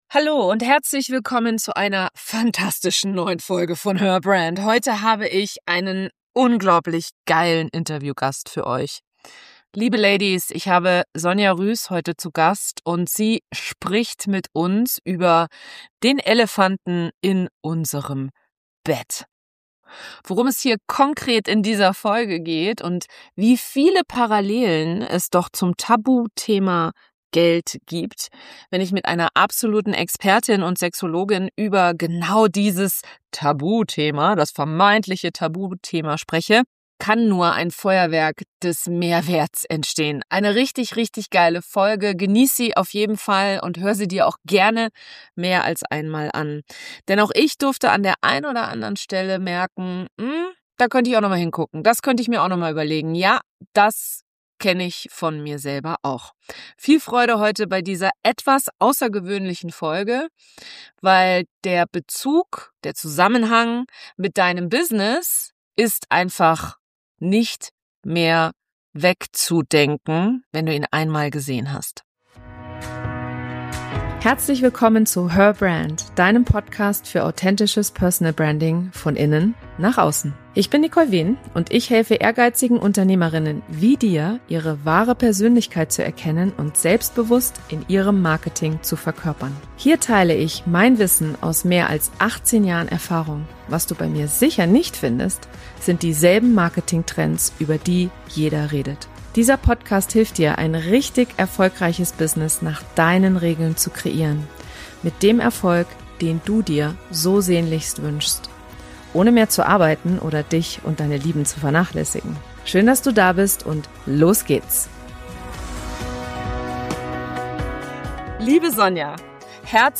In der heutigen Folge wird es feurig, ehrlich und radikal ungeschminkt.